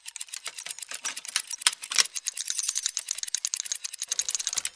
Механическое звучание